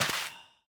Minecraft Version Minecraft Version 1.21.5 Latest Release | Latest Snapshot 1.21.5 / assets / minecraft / sounds / block / soul_sand / break3.ogg Compare With Compare With Latest Release | Latest Snapshot
break3.ogg